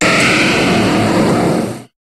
Cri de Métalosse dans Pokémon HOME.